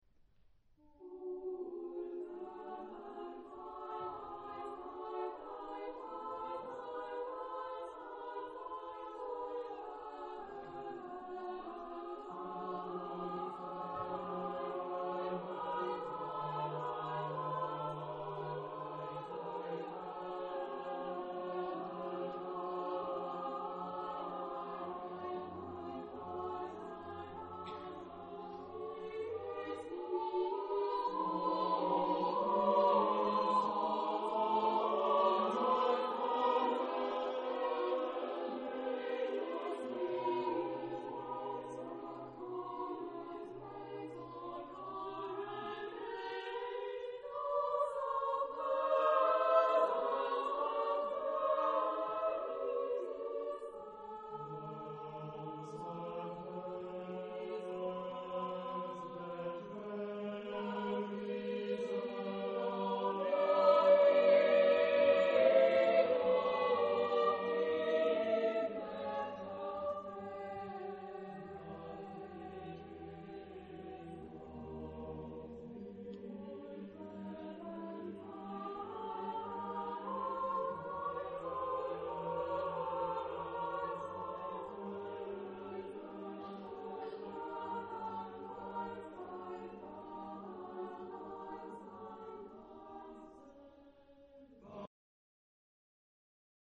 Type of Choir: SSAATTBB  (8 mixed voices )